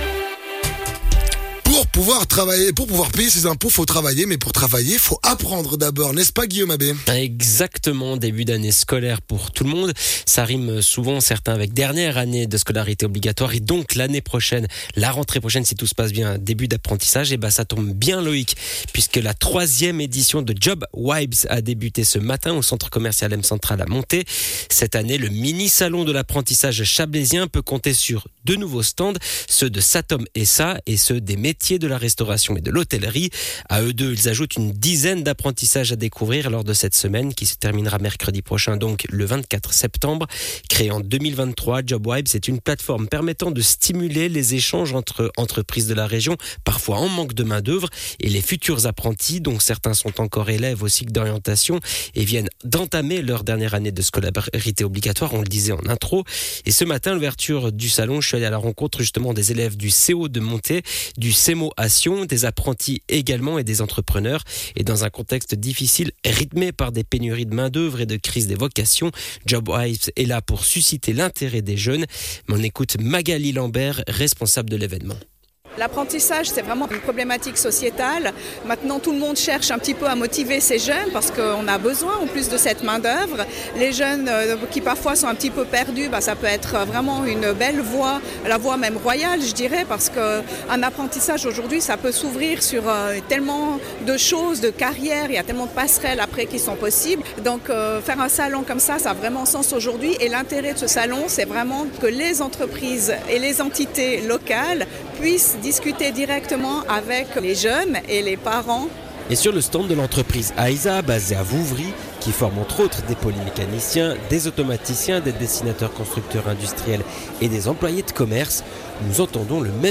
Reportage: Job Vibes à Monthey - 1er jour
Intervenant(e) : Divers Intervenants